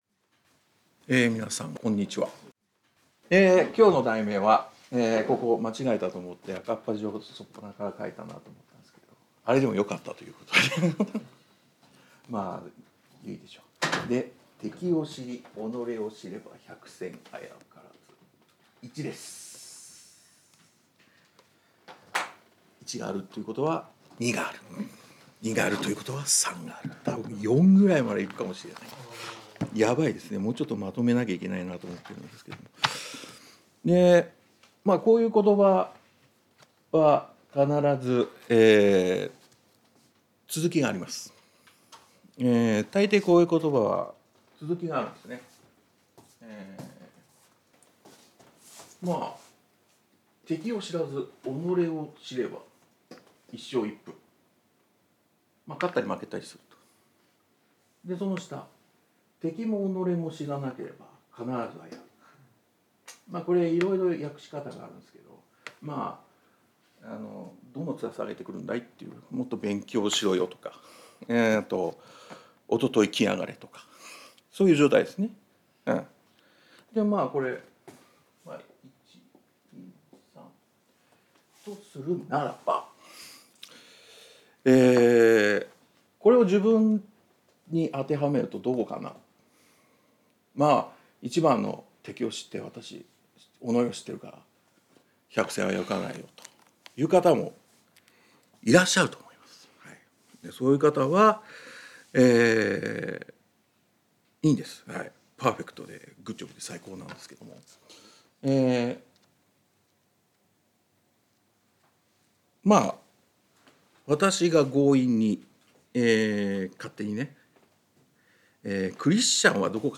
聖書メッセージ No.303